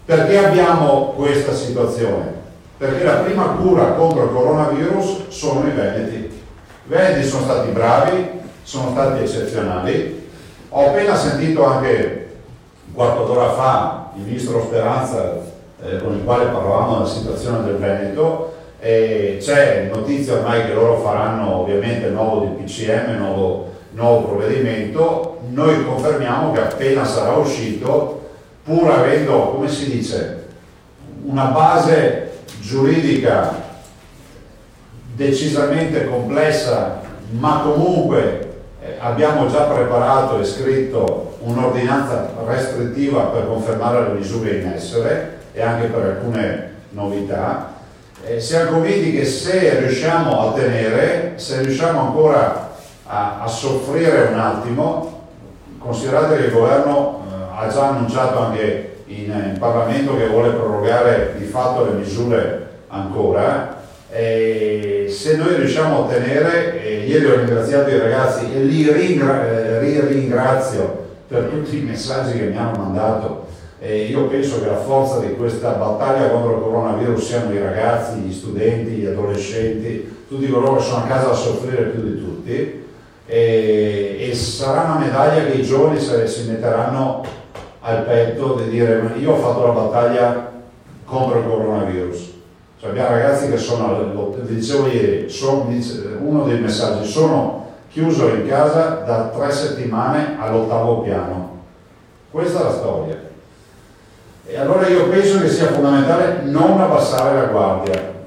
PROVVEDIMENTI, DONAZIONI, TRUFFE E ALTRO…DALLA CONFERENZA STAMPA DI LUCA ZAIA OGGI